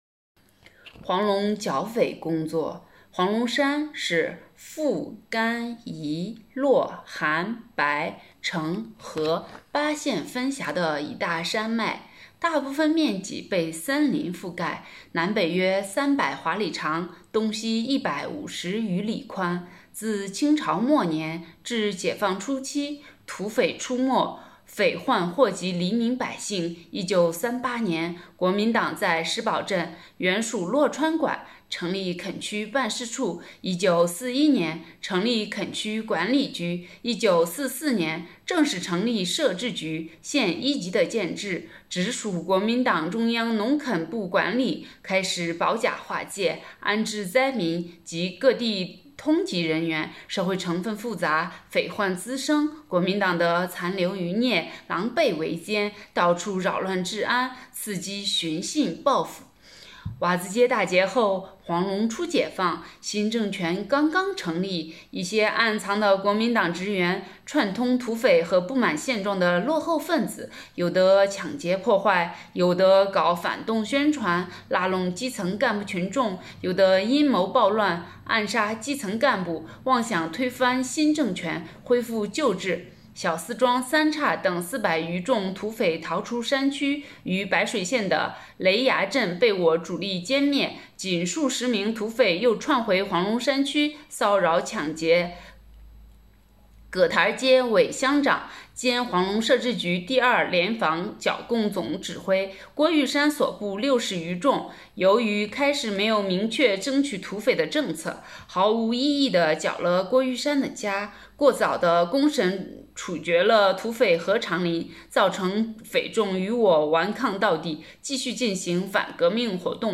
【红色档案诵读展播】黄龙剿匪工作